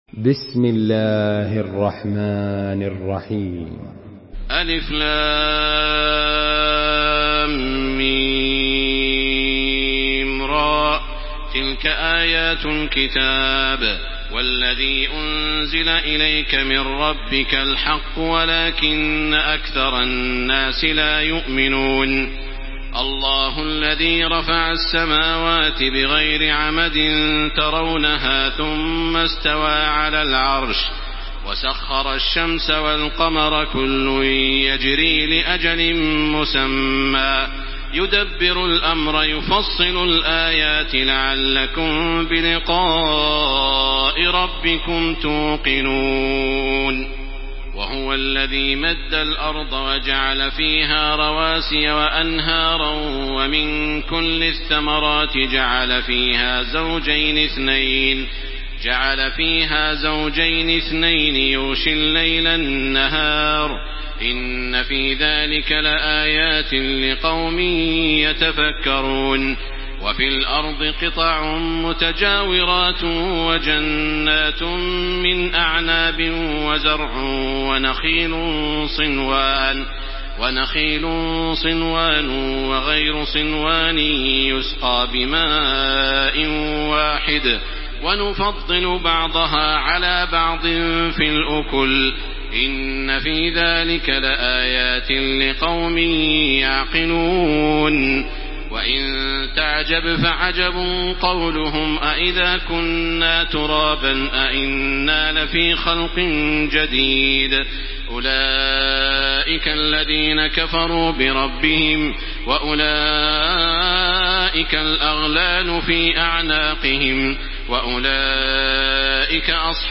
Makkah Taraweeh 1434
Murattal